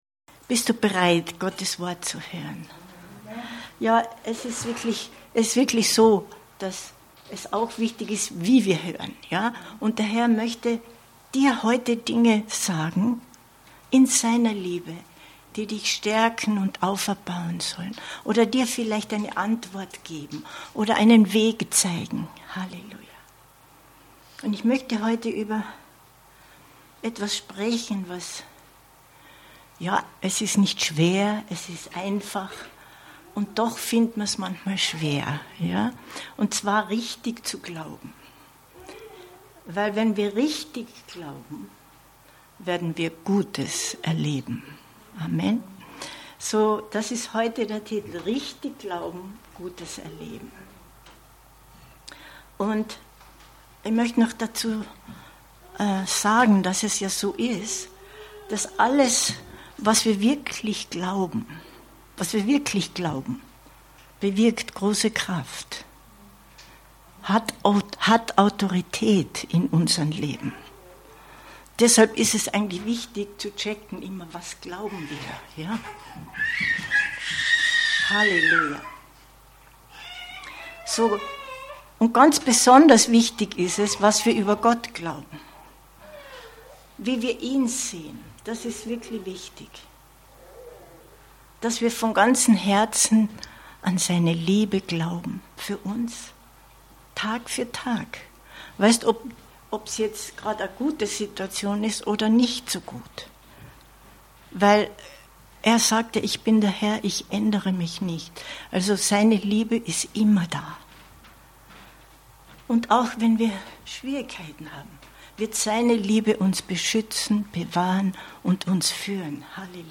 Gutes erleben 22.09.2024 Predigt herunterladen